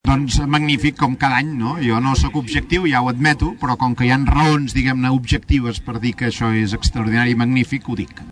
Al final del passant, i en declaracions a Ràdio Taradell, el conseller de la Presidència,